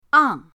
ang4.mp3